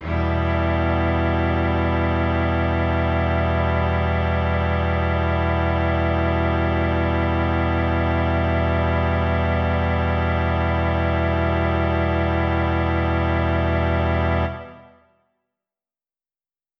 SO_KTron-Cello-Emin.wav